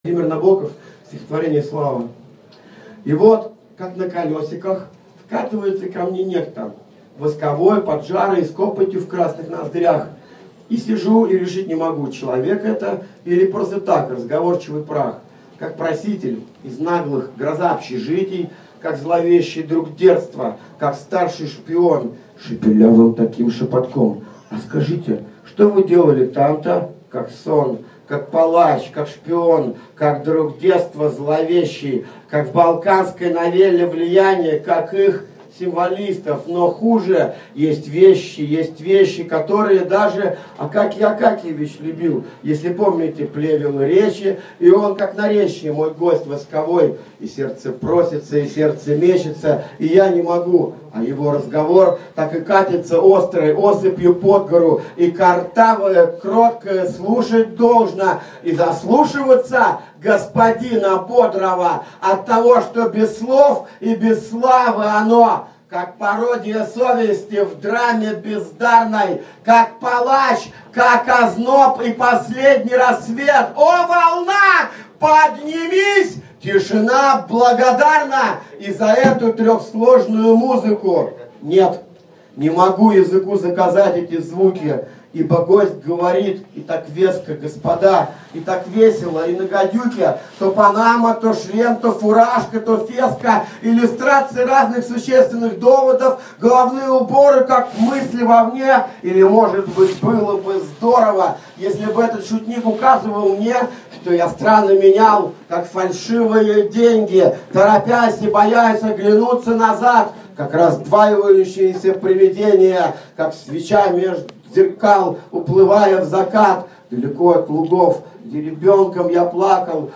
1. «Владимир Набоков – Слава (Читает Лёха Никонов)» /
Nabokov-Slava-Chitaet-Leha-Nikonov-stih-club-ru.mp3